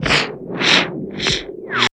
81 SCI-FI -R.wav